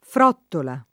[ fr 0 ttola ]